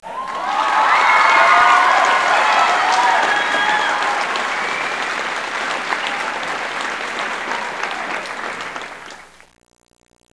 Clap
CLAP.WAV